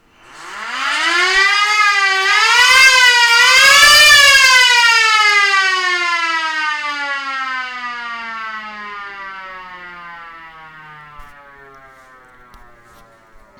Sirena autonoma portatile.
Completamente fatta a mano questa sirena meccanica è decisamente unica nel suo genere.